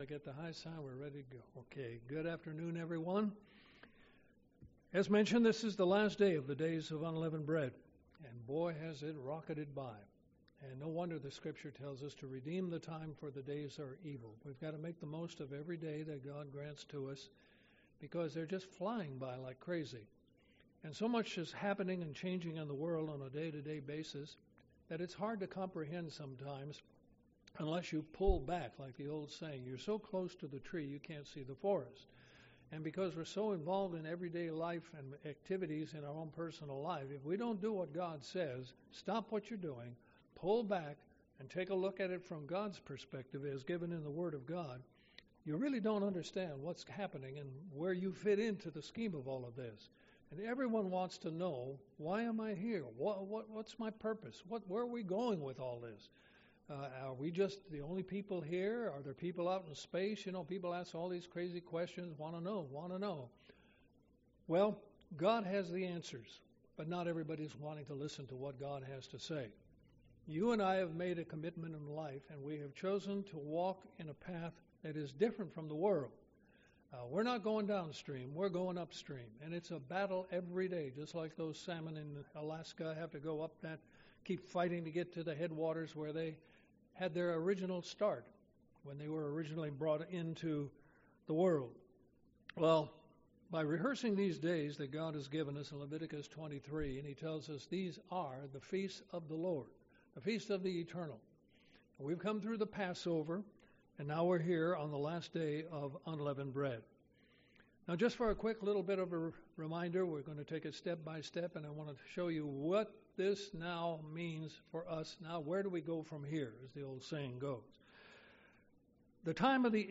Sermons
Given in Columbus, GA Central Georgia